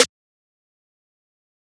snare2.wav